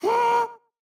Minecraft Version Minecraft Version snapshot Latest Release | Latest Snapshot snapshot / assets / minecraft / sounds / mob / happy_ghast / hurt4.ogg Compare With Compare With Latest Release | Latest Snapshot
hurt4.ogg